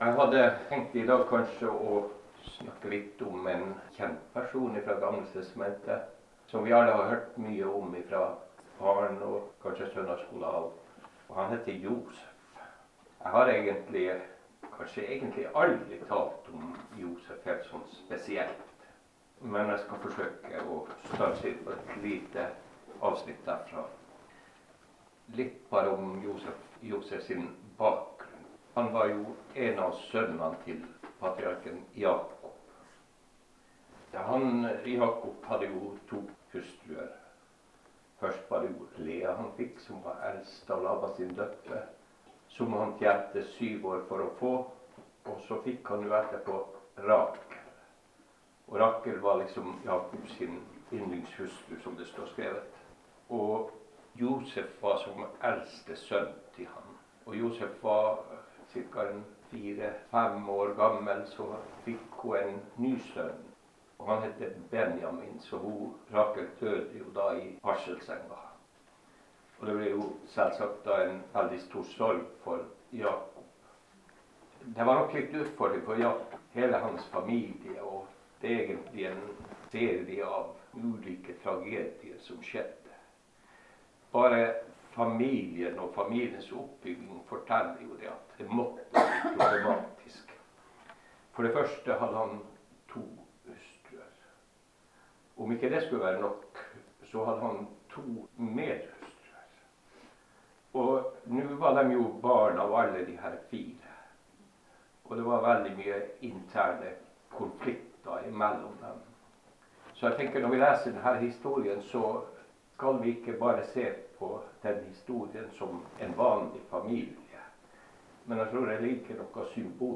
Lytt til andakt: Josef blir høyt opphøyet i Egypt - et forbilde på Jesus - Hør og les Herrens ord!